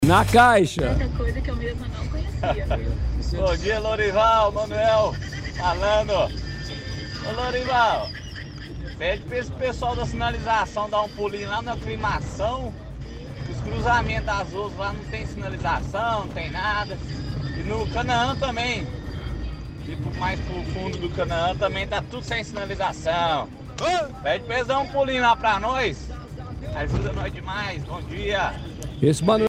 – Ouvinte reclama dos cruzamentos no Aclimação e no Canaã pois está mal sinalizado.